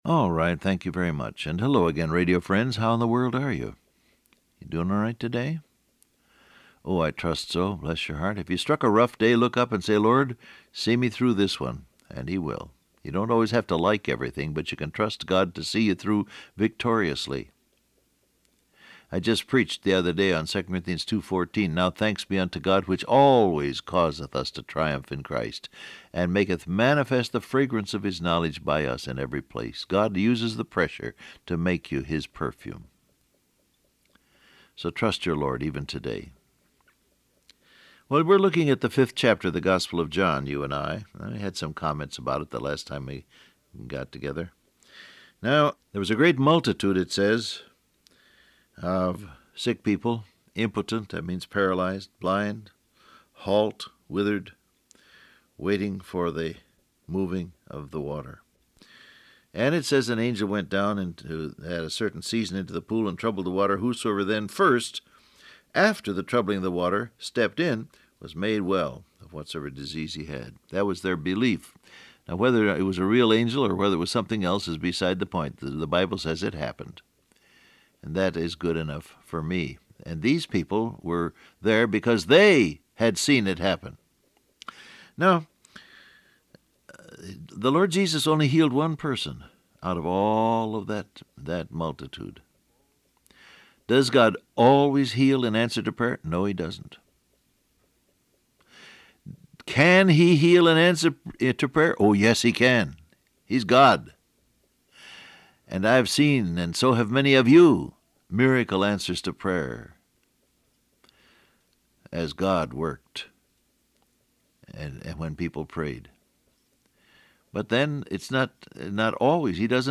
Download Audio Print Broadcast #6840 Scripture: John 5:1-16 Topics: God Knows , Obey , Pray For The Sick , He Can Heal Transcript Facebook Twitter WhatsApp Alright, thank you very much.